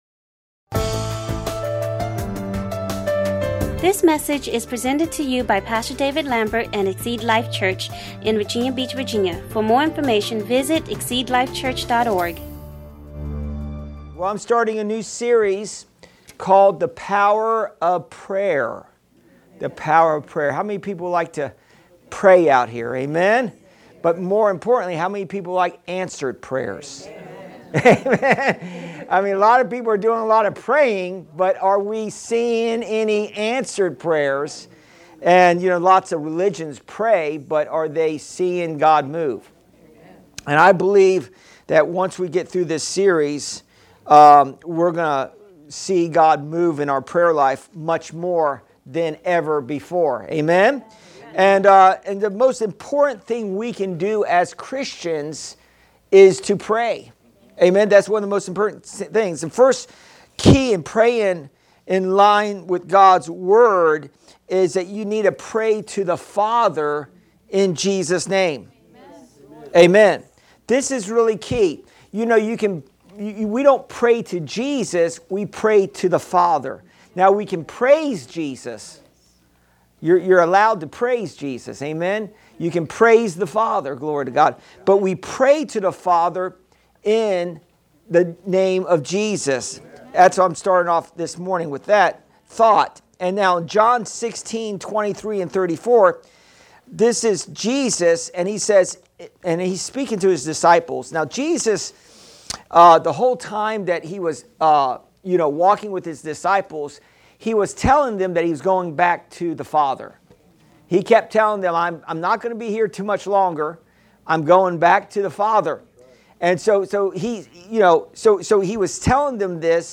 Current Sermon
Exceed Life Church current sermon.